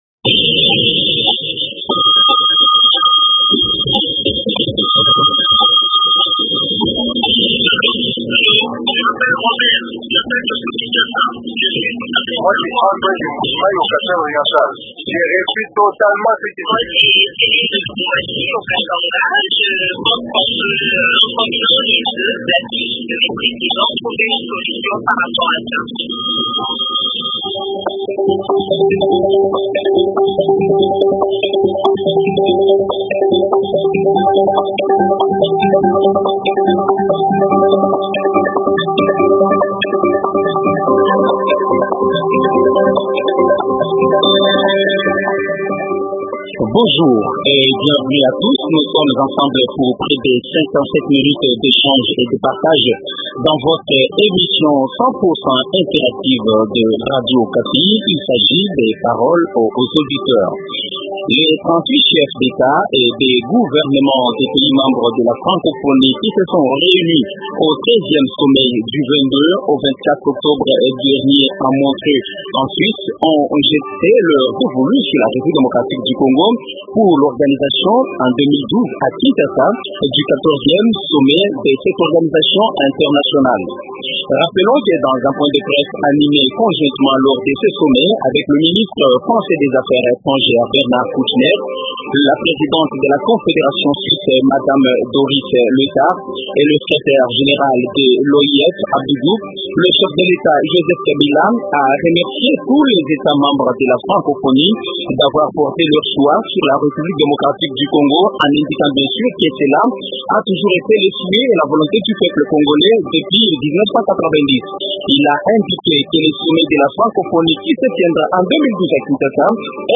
Télécharger Qu’est ce que le sommet de la Francophonie peut apporter à la RDC ? Invité : Isabelle Tshombe, représentante spéciale du chef de l’état au conseil permanent de la Francophonie.